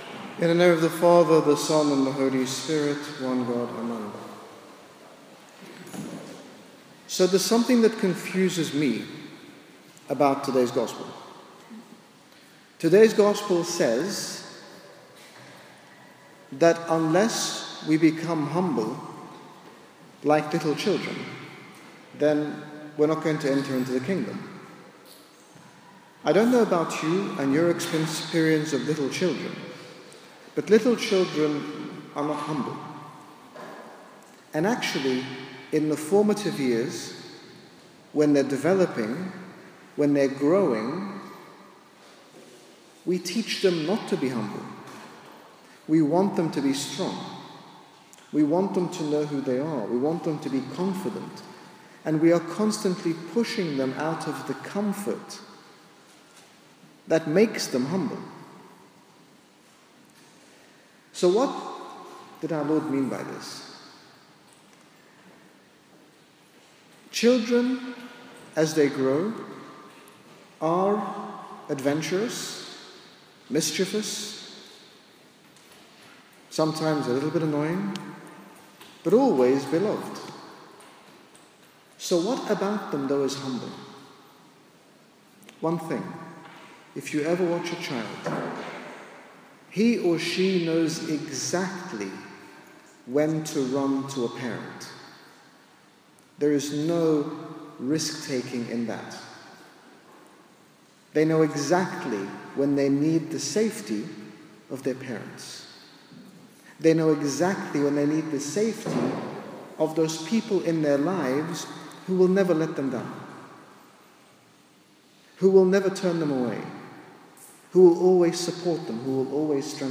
In this short sermon, His Grace Bishop Angaelos speaks about the humility of children equating to knowing when we need to return to our heavenly Father to seek assistance for that which we cannot do on our own.